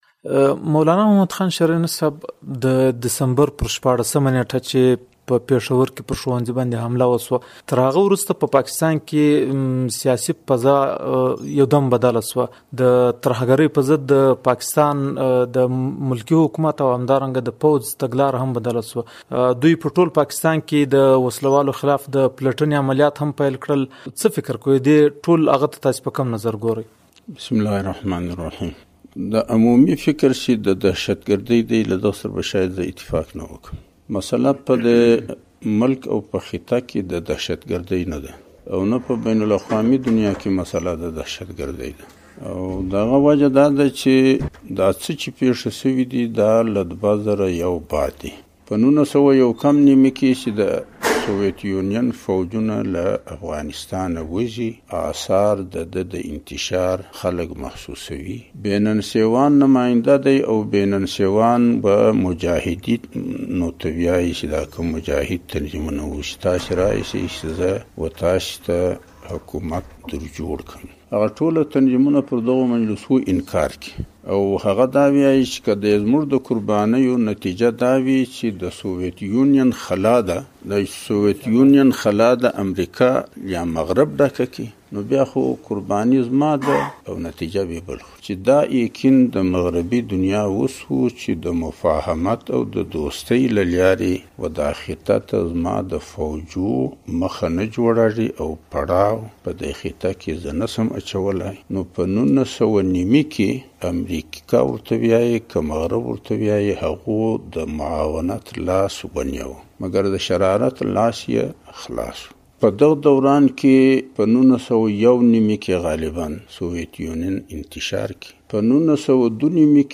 د جمعيت علماى اسلام ګوند له مشر مولانا محمدخان شېراني سره مرکه